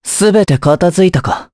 Pavel-Vox_Victory_jp.wav